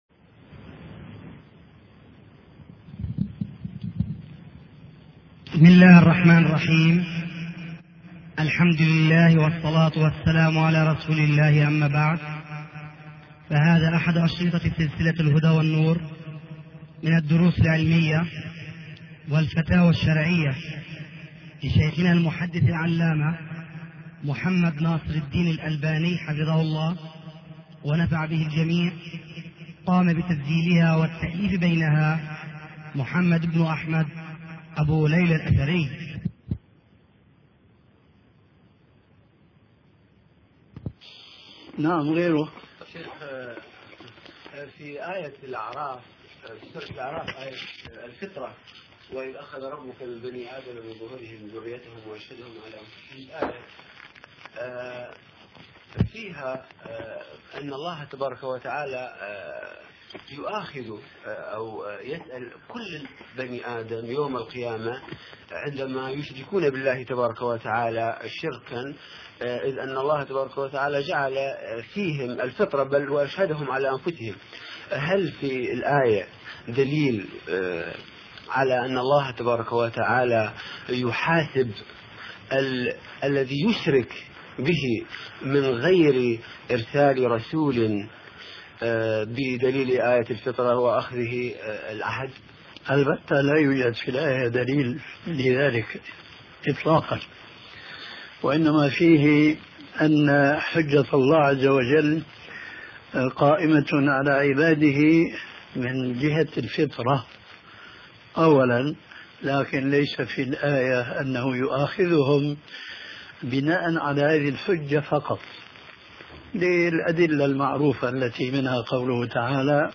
شبكة المعرفة الإسلامية | الدروس | أهل الفترة |محمد ناصر الدين الالباني